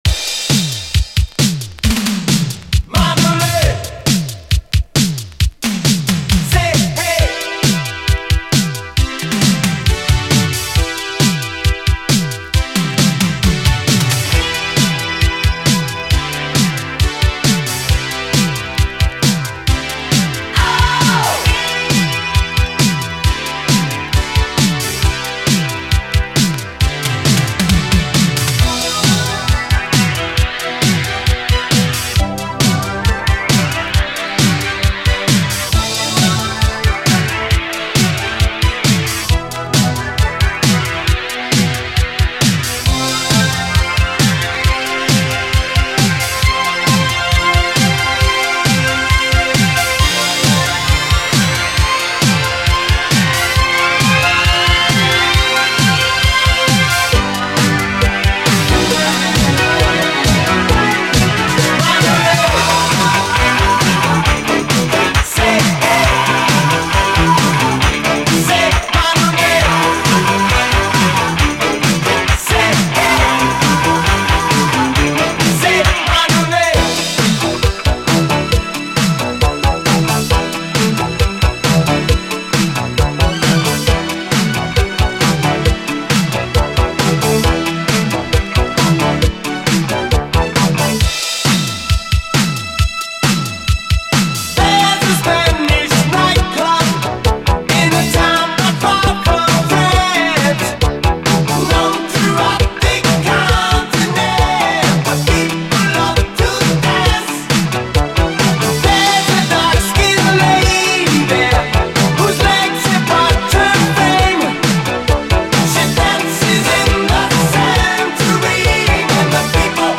DISCO, 7INCH
東亜会館でもヒットしたハイ・エナジー・クラシック！
ギラギラと妖しいシンセ・サウンドとニューウェーヴ感です。